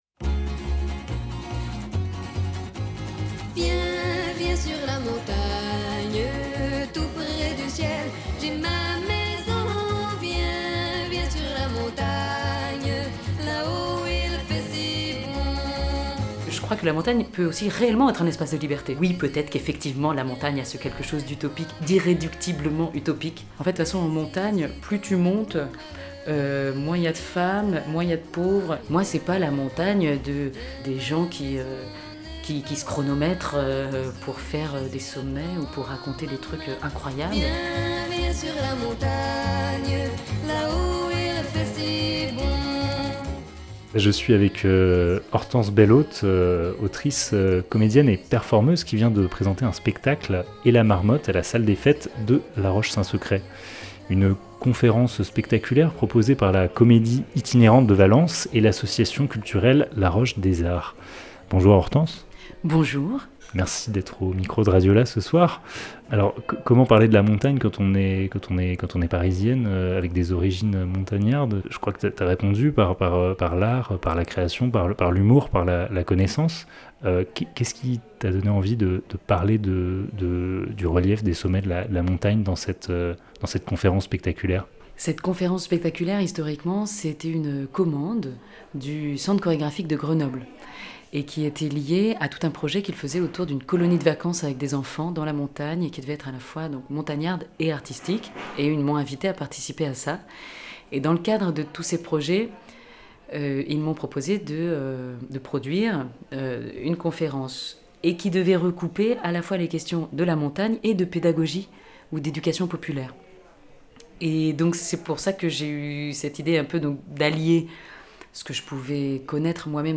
19 octobre 2023 10:40 | Interview